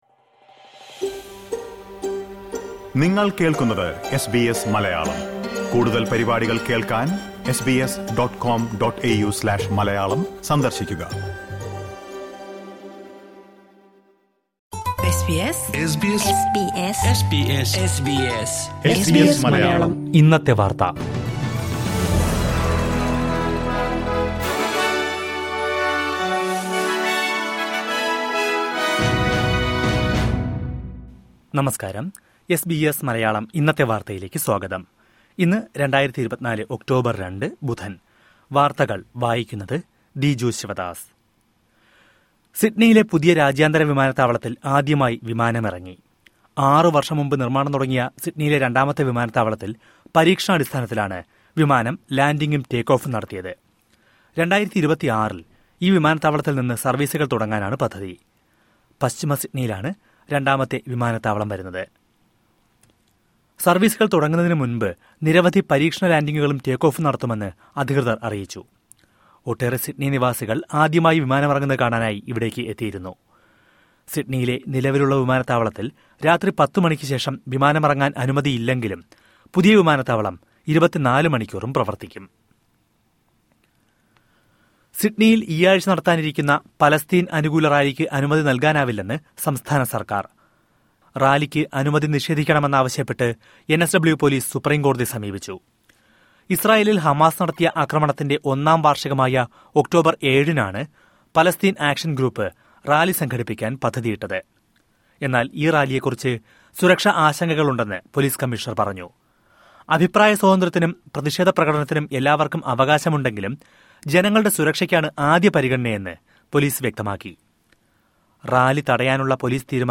2024 ഒക്ടോബര്‍ രണ്ടിലെ ഓസ്‌ട്രേലിയയിലെ ഏറ്റവും പ്രധാന വാര്‍ത്തകള്‍ കേള്‍ക്കാം...